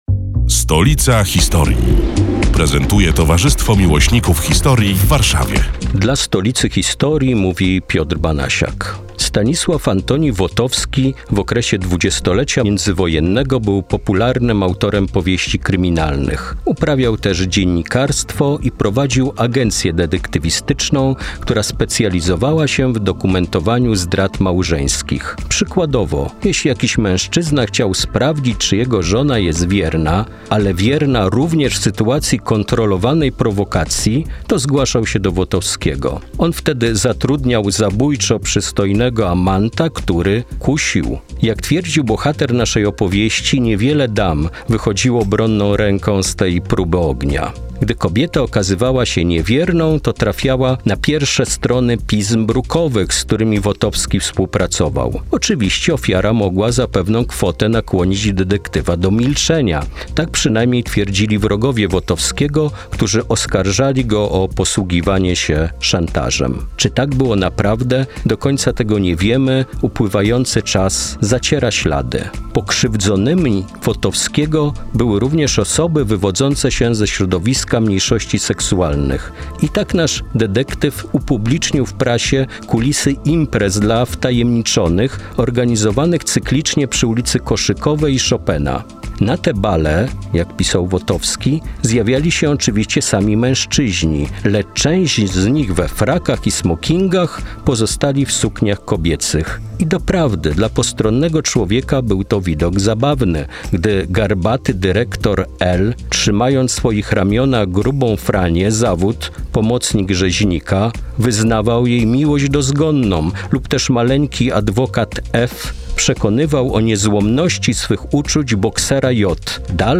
108. felieton pod wspólną nazwą: Stolica historii. Przedstawiają członkowie Towarzystwa Miłośników Historii w Warszawie, które są już od trzech lat emitowane w każdą sobotę, w nieco skróconej wersji, w Radiu Kolor.